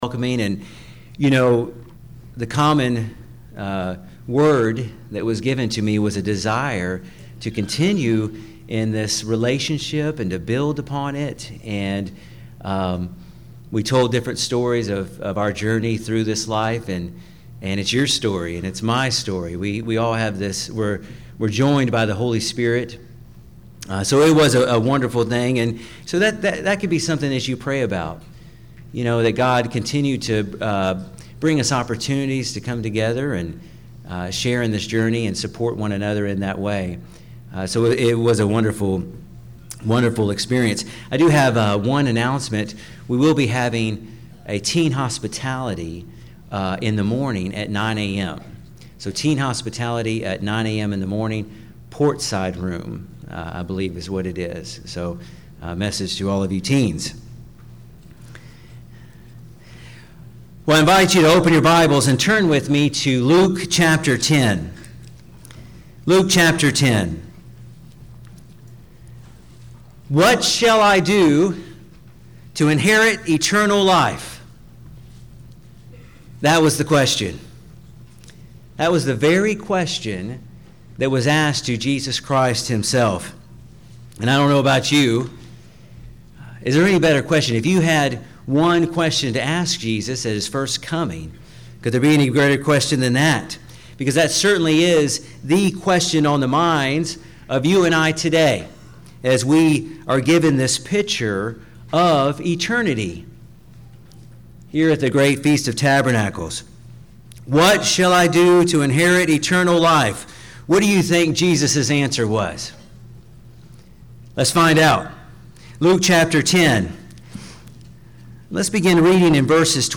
This sermon was given at the Lake Texoma, Texas 2017 Feast site.